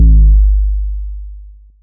Waka 808 - 2 (3).wav